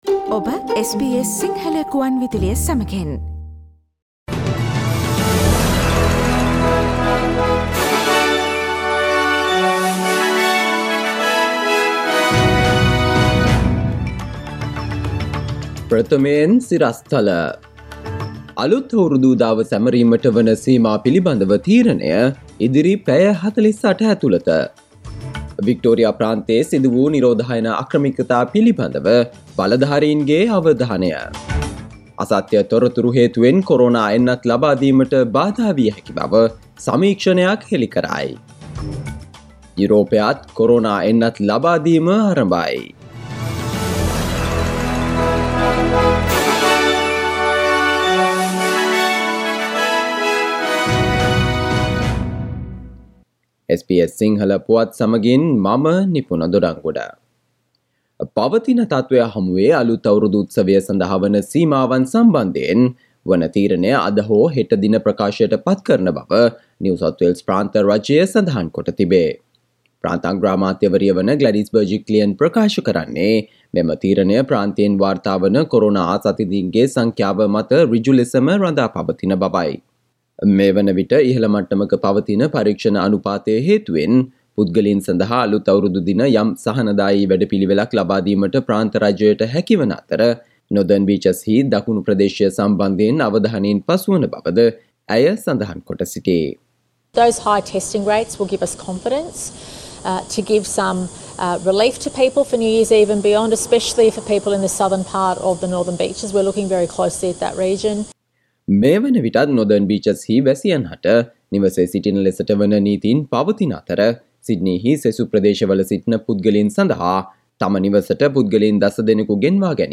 Today’s news bulletin of SBS Sinhala radio – Monday 28 December 2020